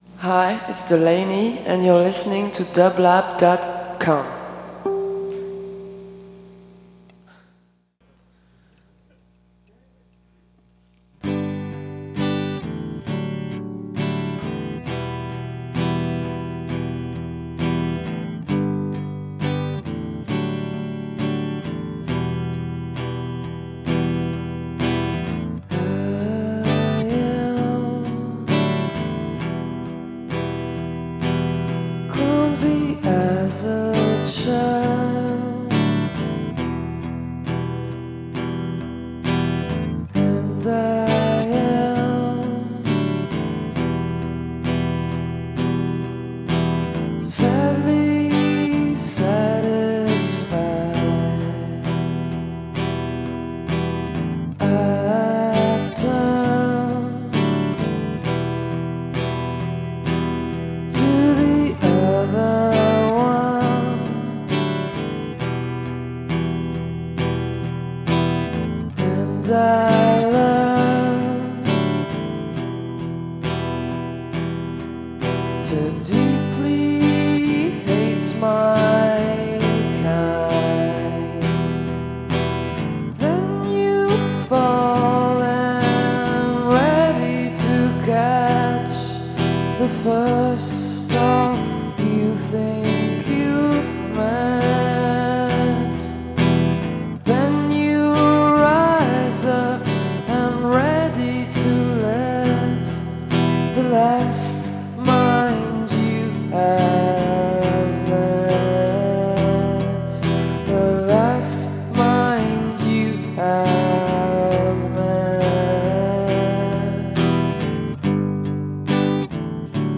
Electronic Folk